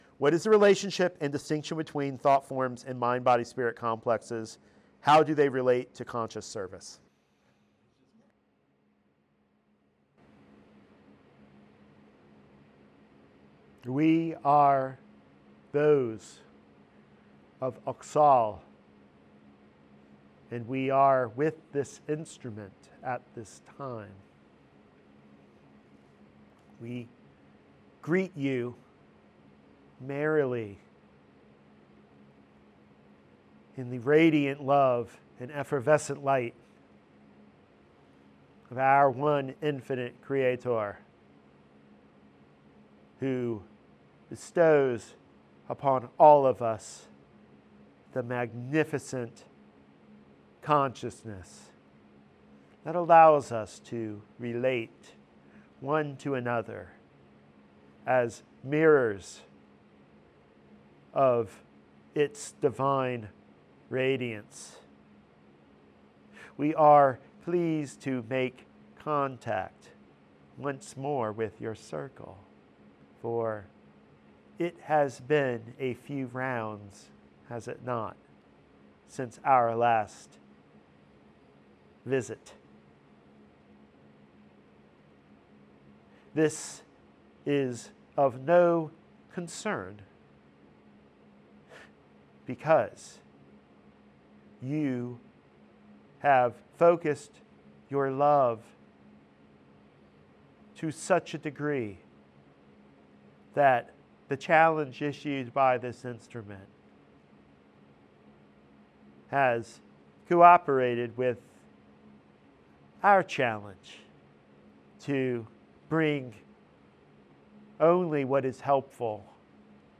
Closing out the 2025 Invitational event, Auxhall visits the circle to invite contemplation on the nature of thought forms and our role as creators within the cosmic hierarchy.